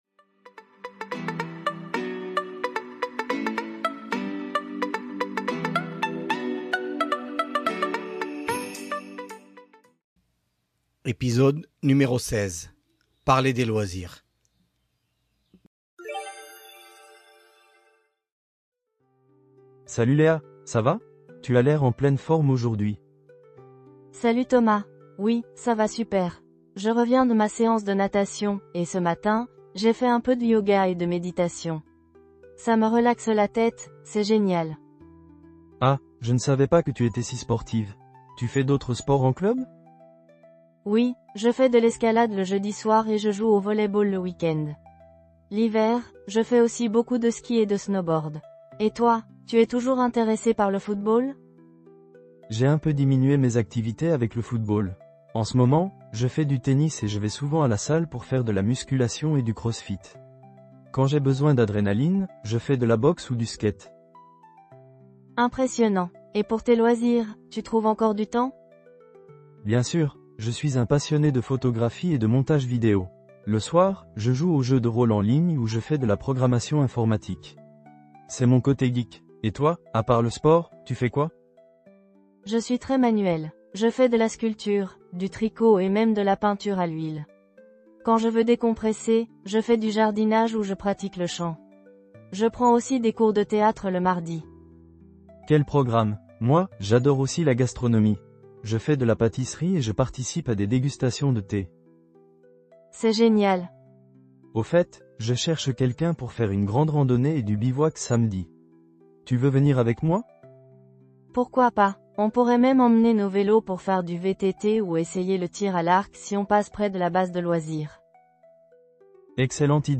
Voici un dialogue pour les élèves de niveau débutant pour apprendre des mots de vocabulaire pour parler des ploisirs et des sports.
016-Podcast-dialogues-Parler-des-loisirs.mp3